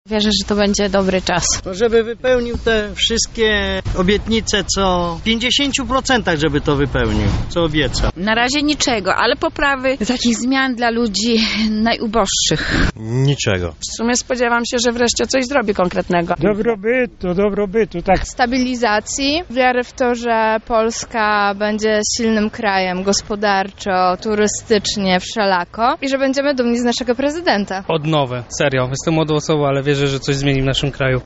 zaprzysiężenie duda sonda – czego lublinianie spodziewają się po prezydenturze
zaprzysiężenie-duda-sonda-czego-lublinianie-spodziewają-się-po-prezydenturze.mp3